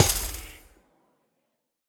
Minecraft Version Minecraft Version latest Latest Release | Latest Snapshot latest / assets / minecraft / sounds / block / trial_spawner / break3.ogg Compare With Compare With Latest Release | Latest Snapshot